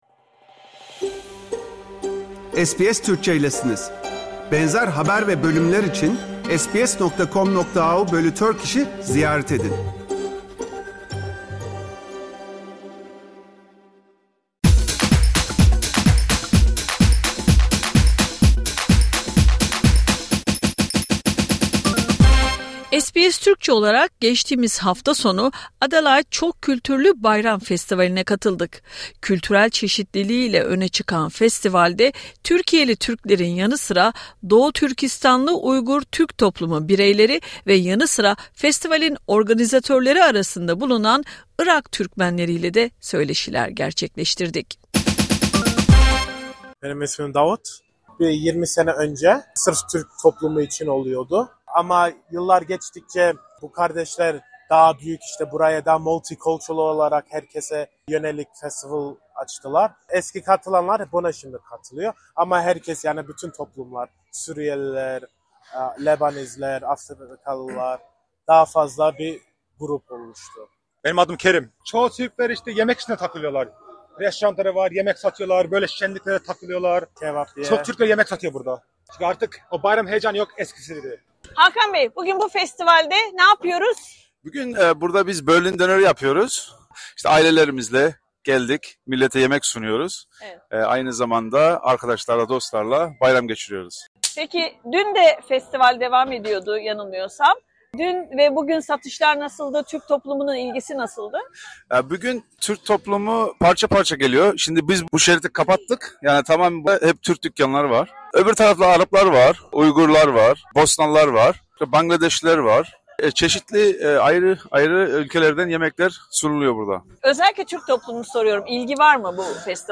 SBS TÜRKÇE olarak 12 ve 13 Nisan 2025 tarihlerinde Adelaide Showground’da yapılan ve Güney Avustralya’nın en büyük festivallerinden biri olan Çok Kültürlü Bayram Festivali’ne katıldık. Kültürel çeşitliliği ile öne çıkan festivalde, Türkiyeli Türklerin yanı sıra Doğu Türkistanlı Uygur Türk toplumu ve yanı sıra festivalin organizatörleri arasında bulunan Irak Türkmenleriyle de söyleşiler yaptık.
Festivale katılan ve yemek karavanlarında ya da tezgahlarda Türk kebabı, gözlemesi ve çeşitli ürünler satan Adelaide Türk toplum bireyleri SBS Türkçe mikrofonlarına konuşarak duygu ve düşüncelerini paylaştı.